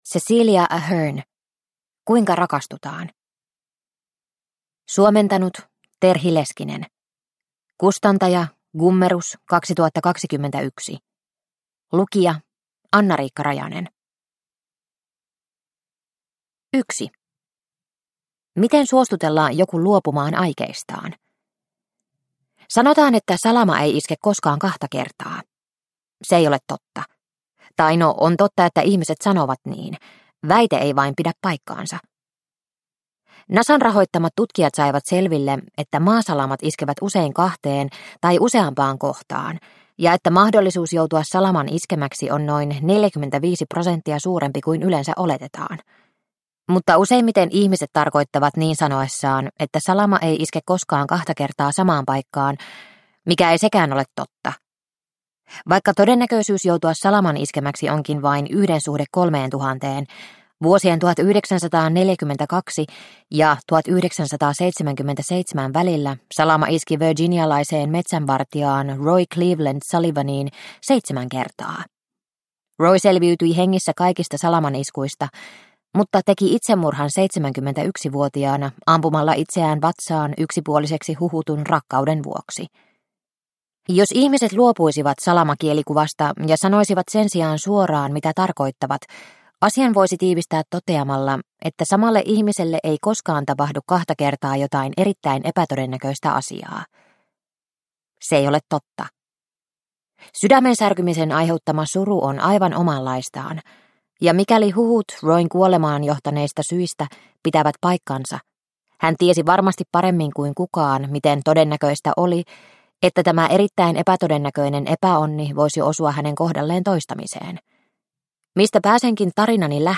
Kuinka rakastutaan – Ljudbok – Laddas ner